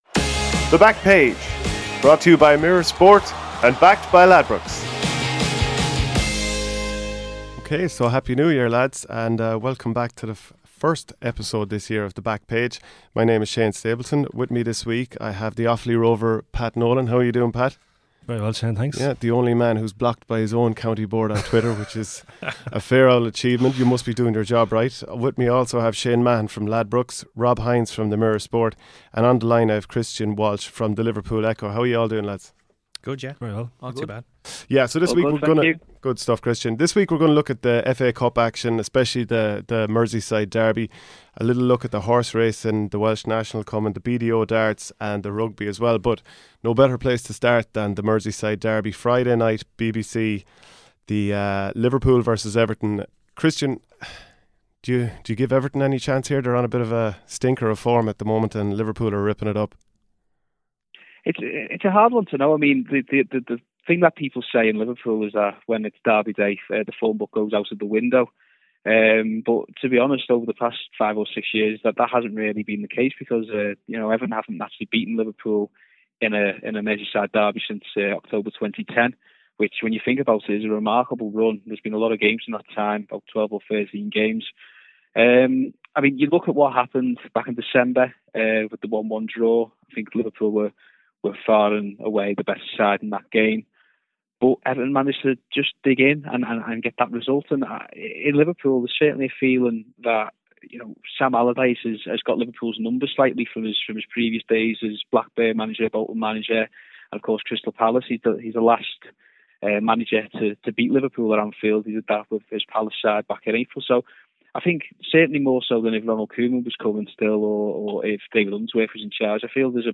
With conditions set to be fairly bad at Chepstow, the panel pick out some runners who will relish the heavy ground. PRO14 action is again up for debate, with Leinster facing Ulster and Munster hosting Connacht at Thomond Park and we also look at the betting for the BDO Darts.